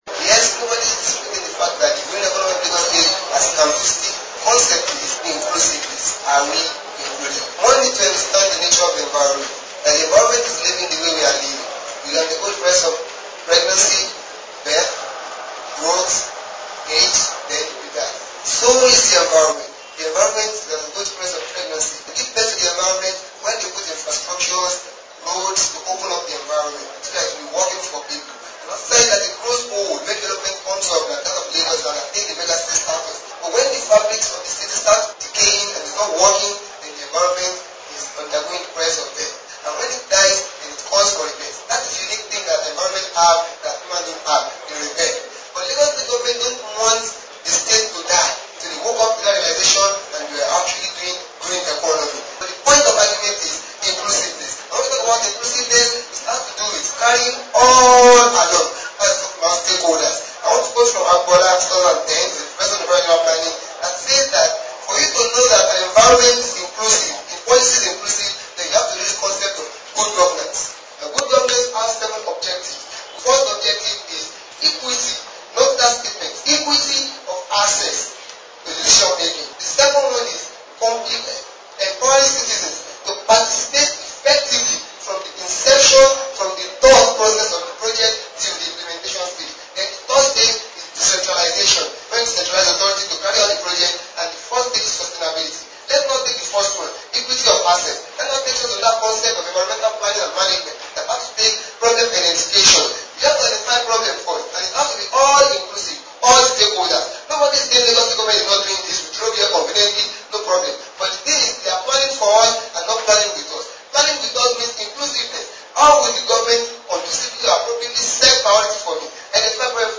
speaking against non-inclusiveness,in relation to green economy in Lagos State during the green challenge debate organised for tertiary institutions in the State.